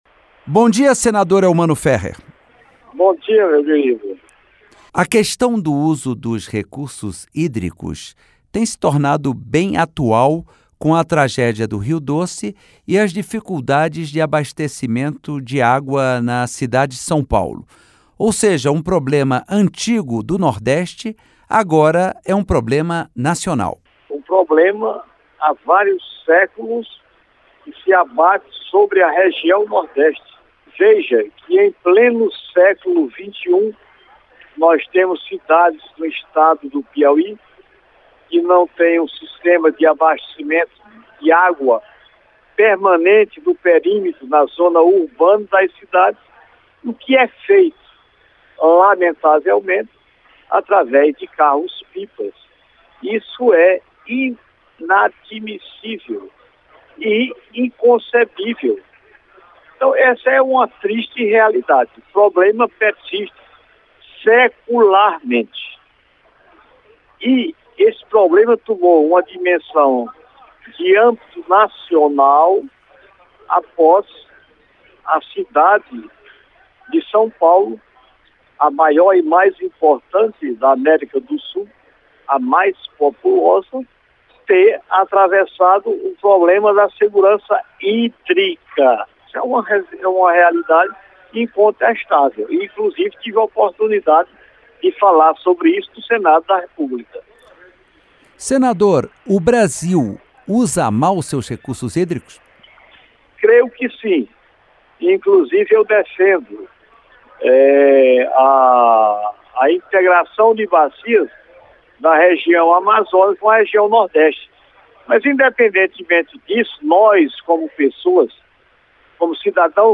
Entrevista com o senador Elmano Férrer (PTB-PI).